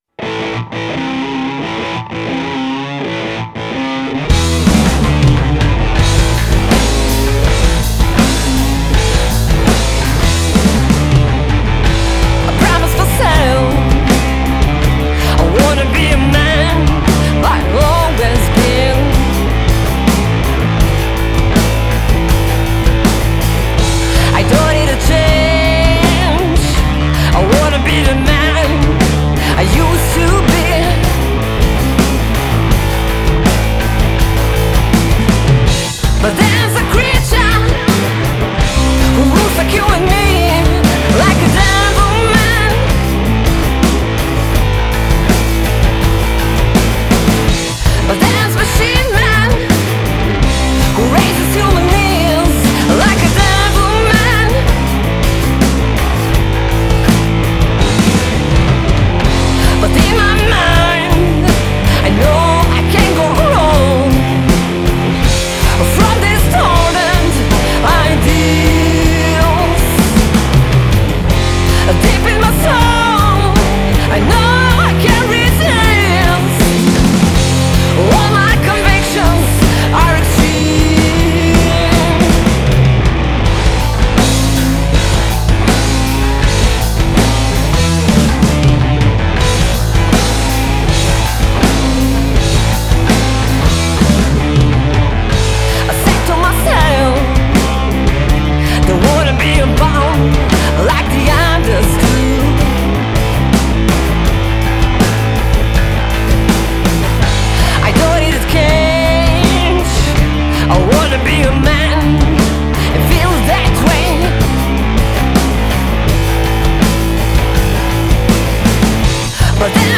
blues-rock trio